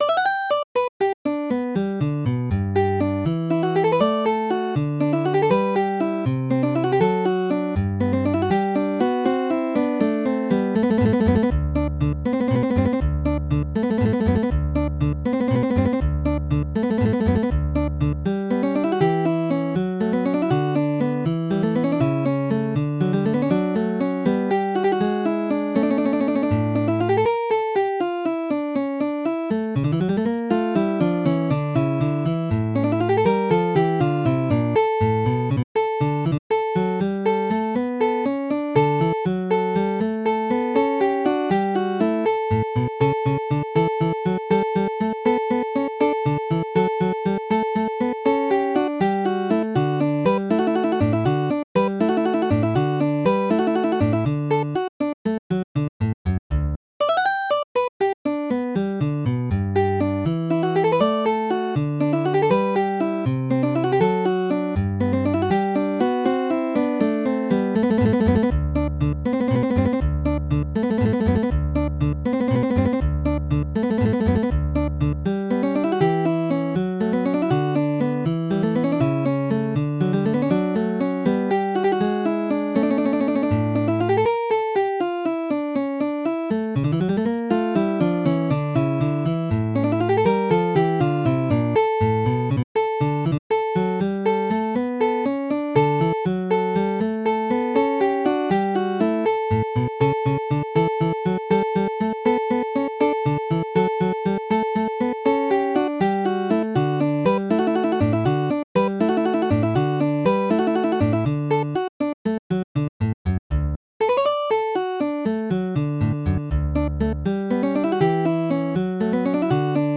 ソナタト長調
Midi音楽が聴けます 3 230円